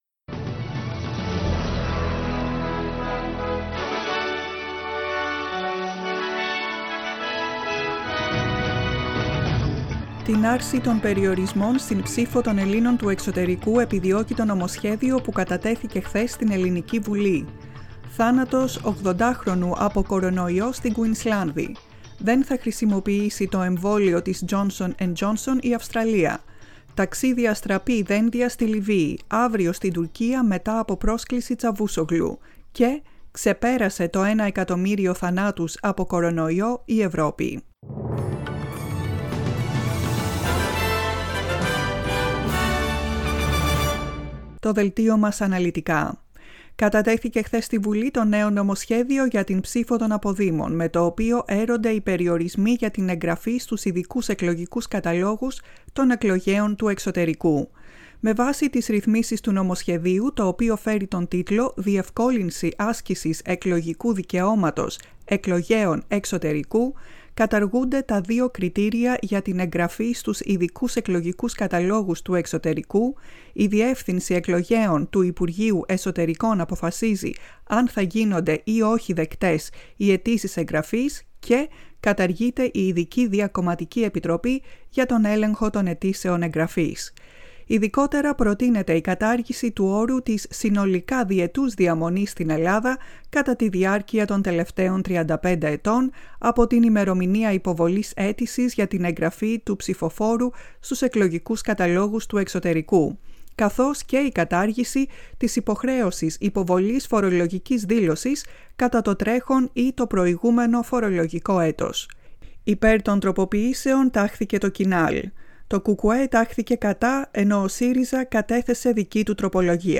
Δελτίο ειδήσεων στα ελληνικά, 13.4.2021
Το κεντρικό δελτίο ειδήσεων του Ελληνικού Προγράμματος.